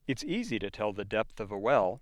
Sounds for Exercise I Chapter 4 spoken by an American Speaker